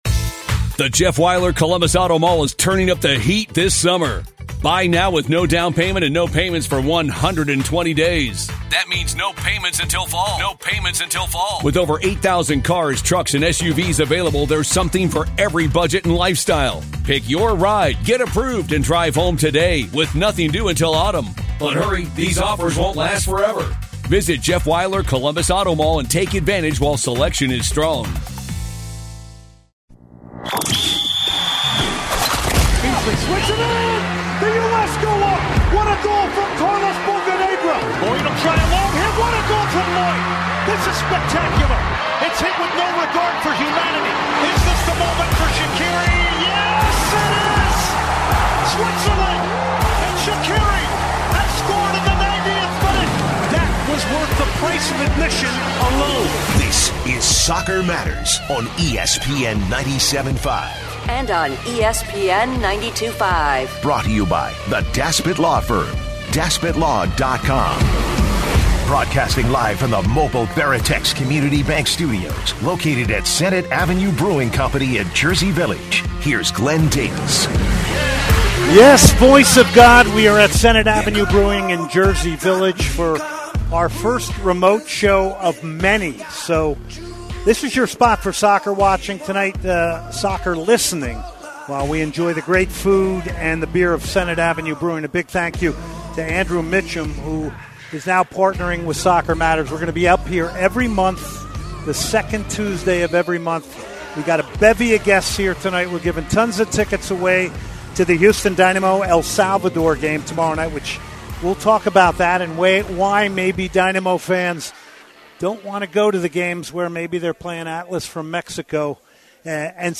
LIVE from Senate Ave Brewing